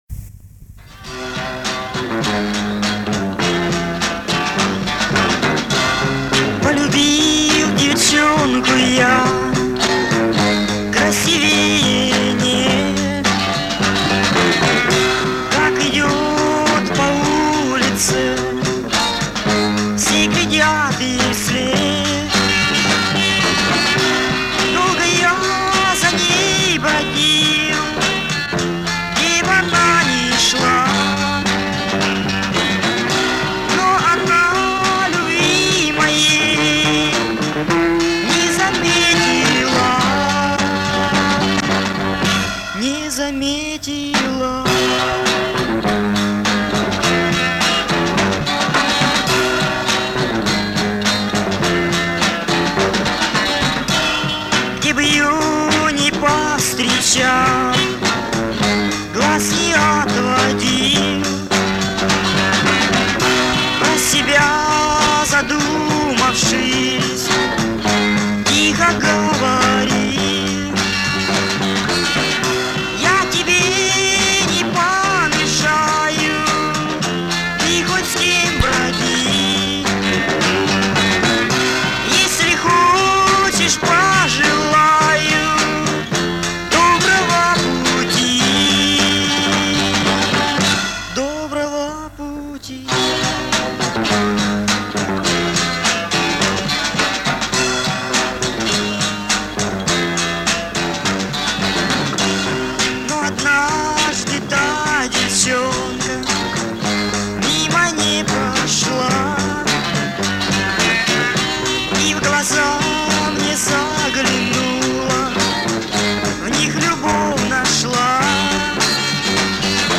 У кого есть дворовая песня 70 Полюбил девчонку я. Заранее благодарен.